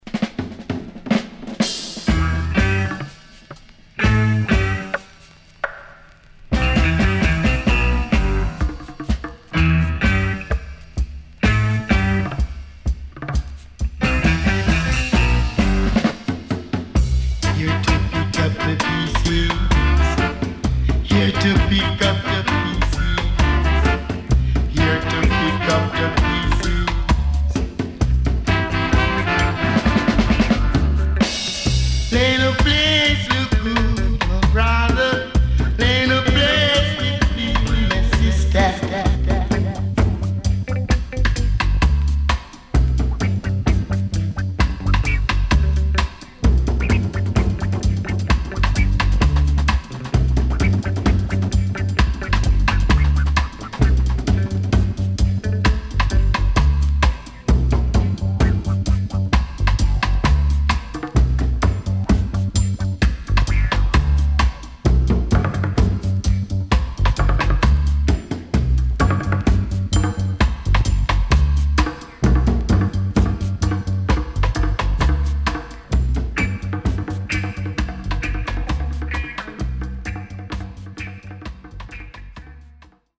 Fine Roots & Dubwise
SIDE A:少しノイズ入りますが良好です。